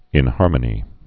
(ĭn-härmə-nē)